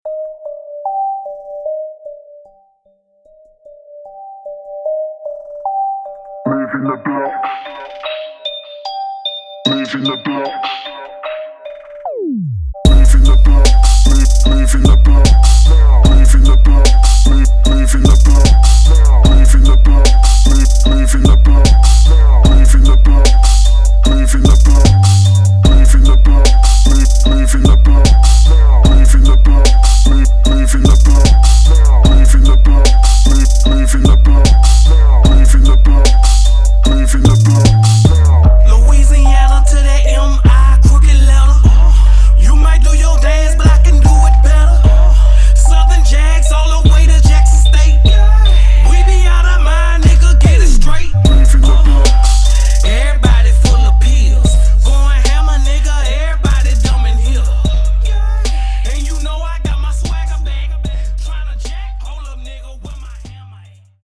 Radio / Extended Mix